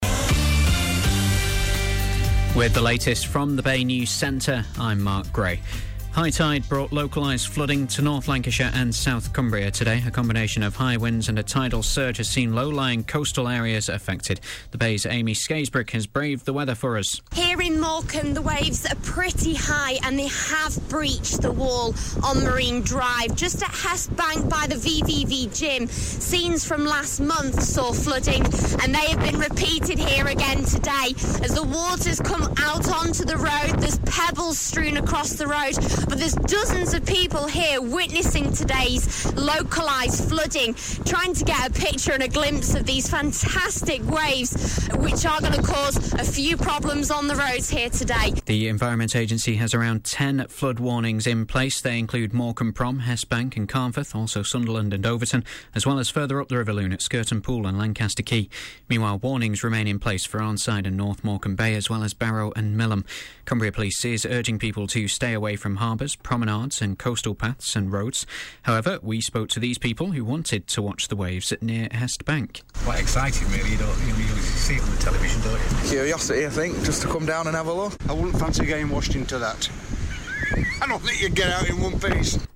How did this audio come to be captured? Here is my report from the scene of flooding along Morecambe's Promenade on Marine Drive near Hest Bank on Friday 3rd January 2014. My reports were broadcast in The Bay's news bulletins along with the vox pops that I conducted with local people who had gathered on the prom to witness the flooding.